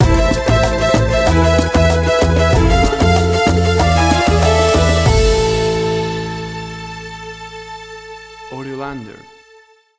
WAV Sample Rate: 16-Bit stereo, 44.1 kHz
Tempo (BPM): 95